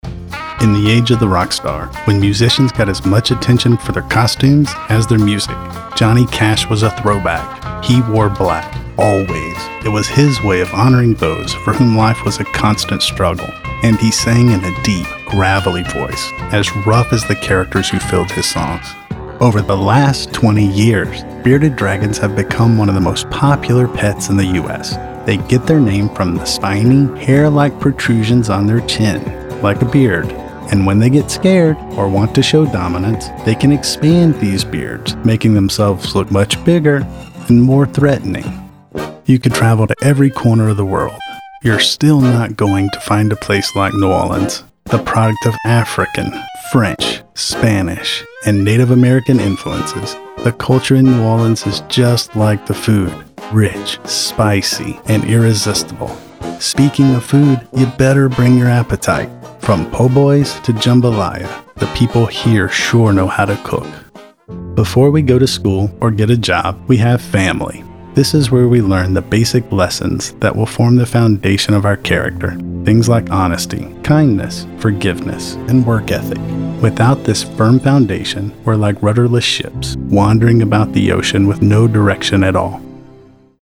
Voice Actor
Narration Demo (mp3)